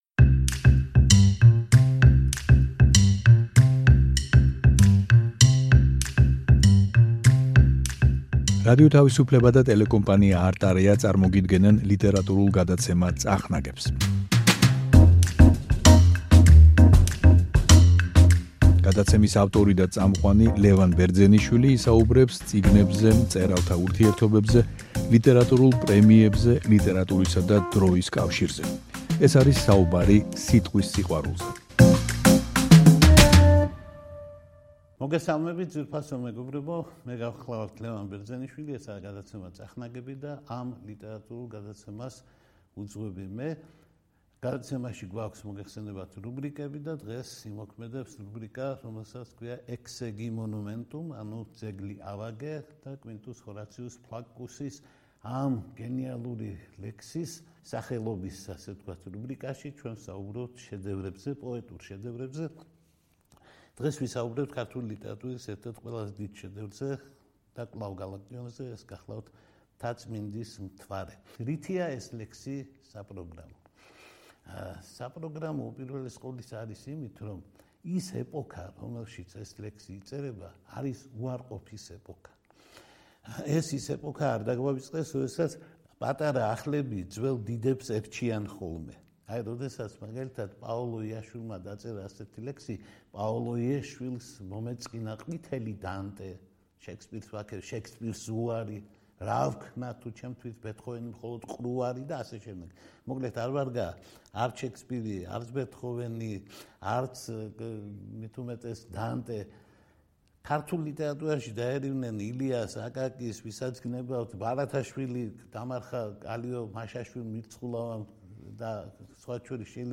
ლიტერატურული გადაცემა „წახნაგები“ რუბრიკით exegi monumenum („ძეგლი ავაგე“), რომელშიც, როგორც წესი, მსოფლიო ლიტერატურის დიდ პოეტურ ნიმუშებზე ვამახვილებთ თქვენს ყურადღებას, ამჟამად გთავაზობთ საუბარს გალაკტიონის შედევრზე, რომელსაც პოეტი „საპროგრამოს“ უწოდებდა, – „მთაწმინდის მთვარეზე“.